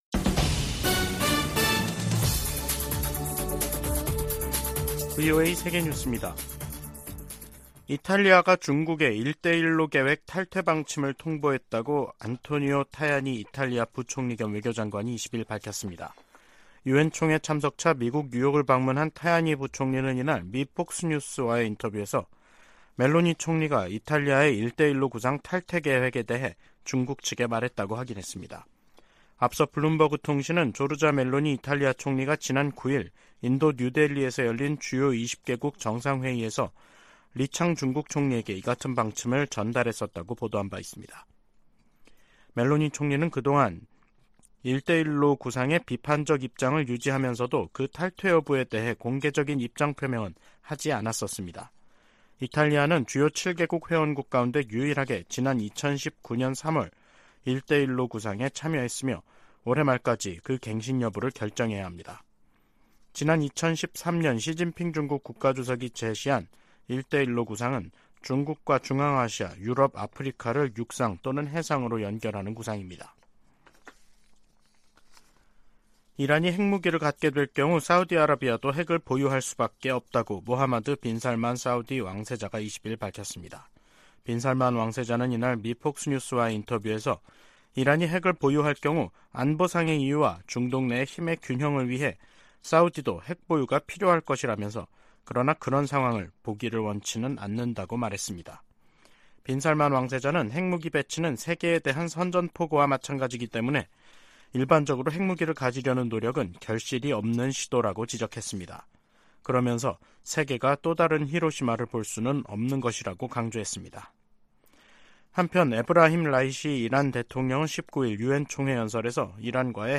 VOA 한국어 간판 뉴스 프로그램 '뉴스 투데이', 2023년 9월 21일 3부 방송입니다. 윤석열 한국 대통령이 유엔총회 연설에서 북한의 핵과 탄도미사일 개발이 세계 평화에 대한 중대한 도전이라고 규탄했습니다.